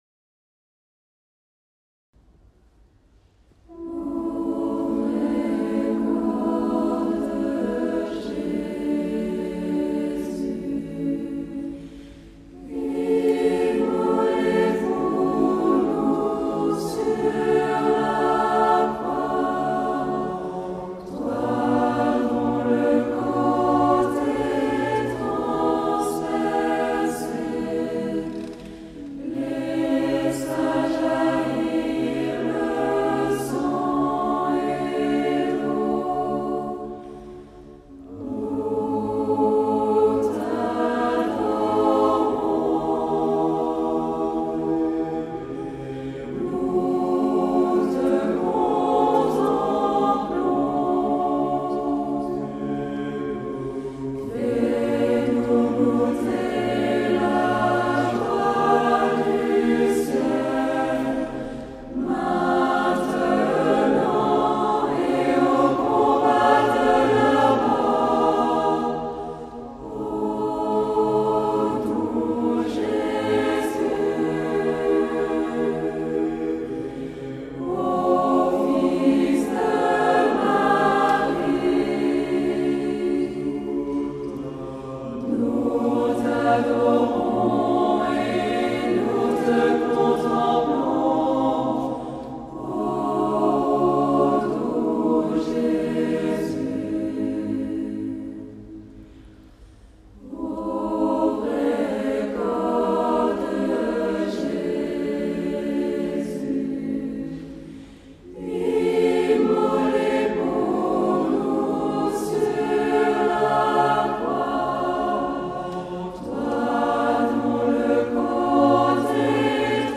Communion Ô Vrai corps de Jésus Ft5
Lieu Eglise Saint-François-de-Paule (Fréjus)